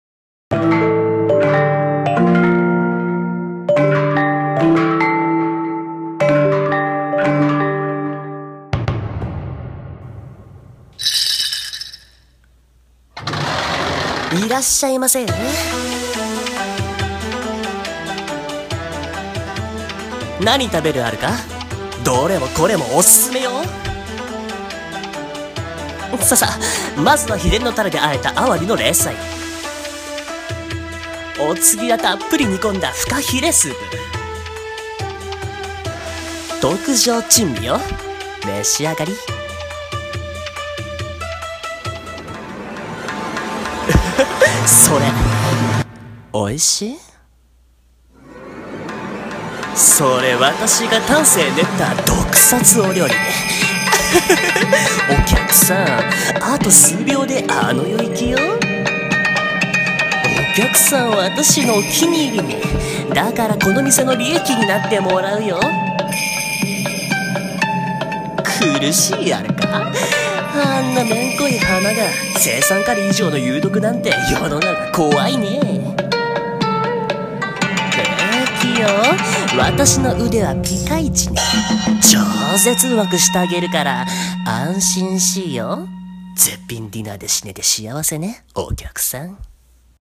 【声劇】珍味の鈴蘭中華店